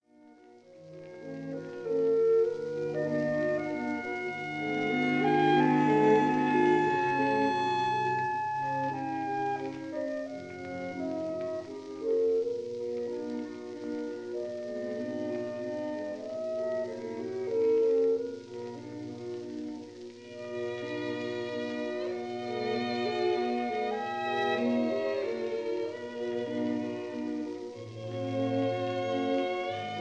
Recorded in Société suisse de radiodiffusion
studio, Geneva in July 1948